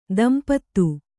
♪ dampattu